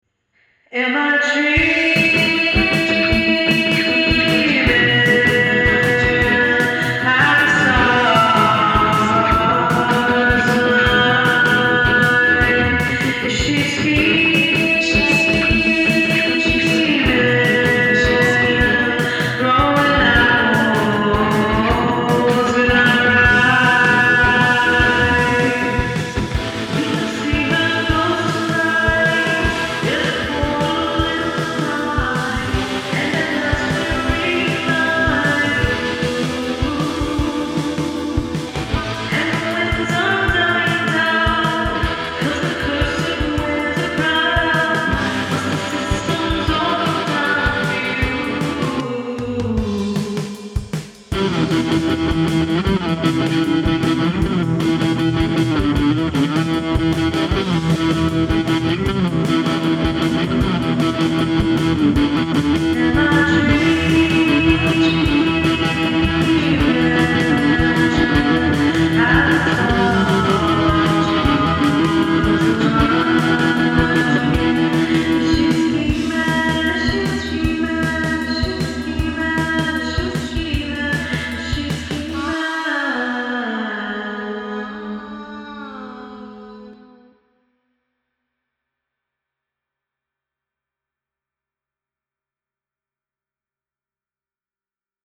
Liars & Believers transforms ancient, divine justice into contemporary, thrashing vengeance – in a new theatrical adaptation with driving text, kinetic physicality, and a Riot-Grrrl punk band.
These are the first demo recordings